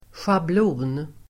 Ladda ner uttalet
Uttal: [sjabl'o:n]